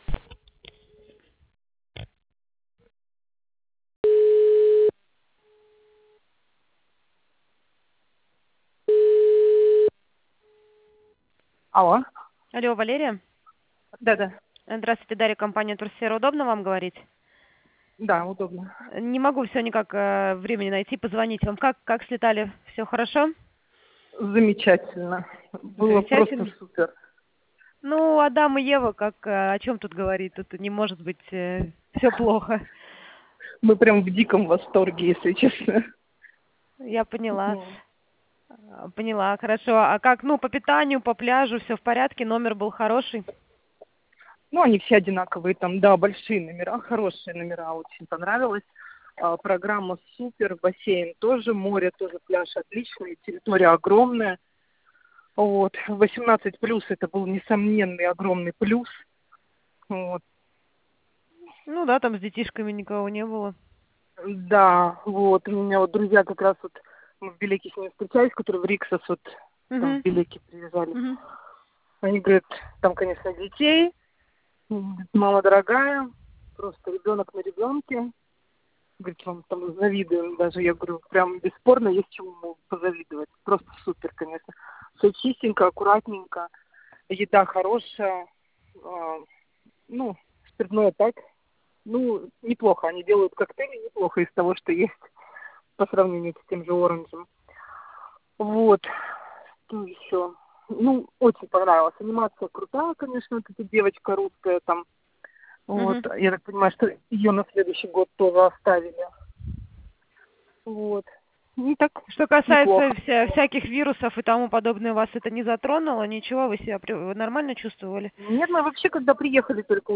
Аудио отзыв об отдыхе в Турции в Августе 2017